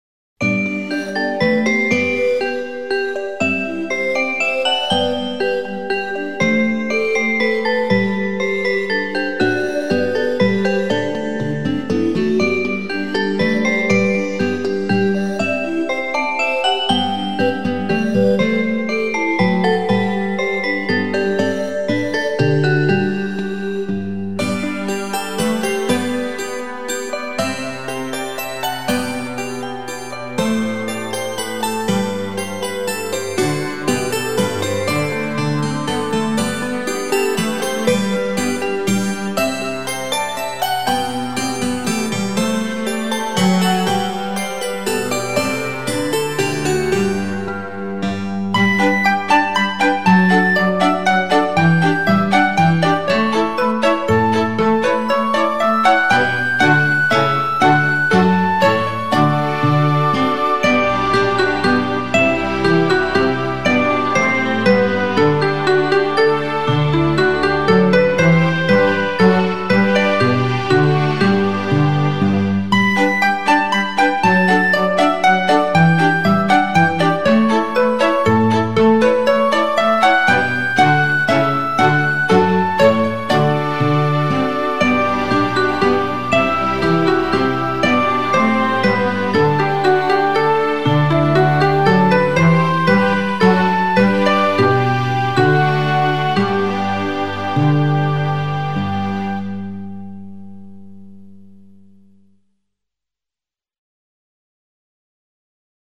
精致．细腻．满足宝宝嫩敏感的小耳朵